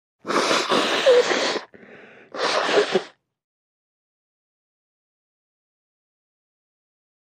Blow Nose | Sneak On The Lot
Nose Blow, Comical 1; Short, Medium Perspective.